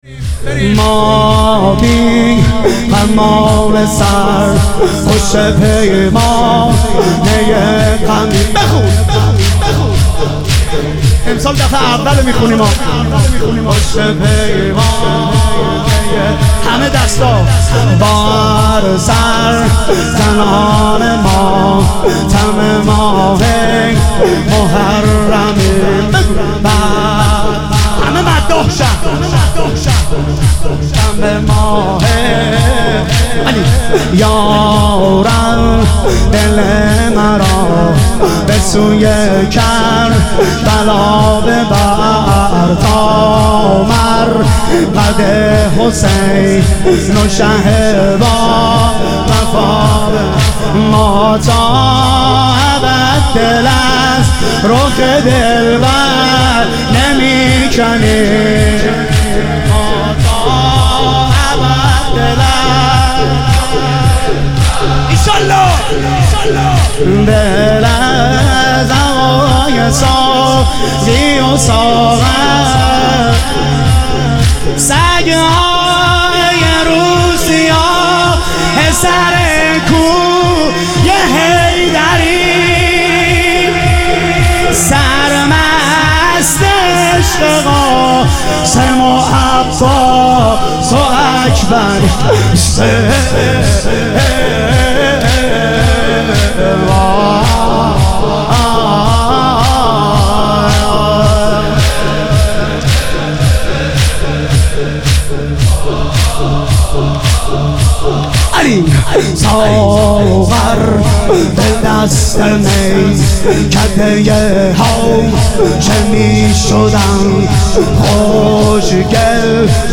محرم الحرام - تک - 3 - 1401